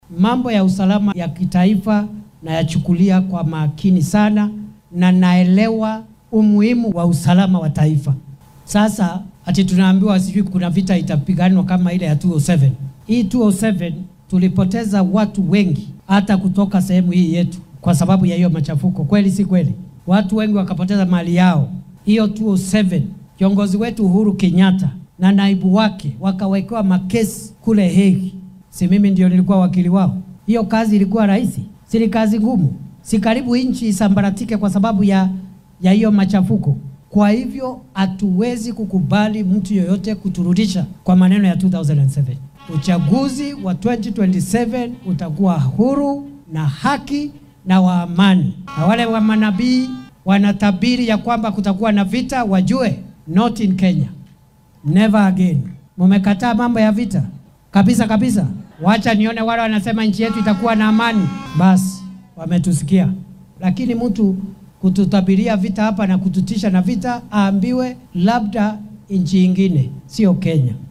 Xilli uu maanta munaasabad kaniiseed uga qayb galayay ismaamulka Busia ayuu madaxweyne ku xigeenka qaranka hadalka mas’uulkii ka horreeyay ku tilmaamay mid khatar ah oo aan laga fiirsan.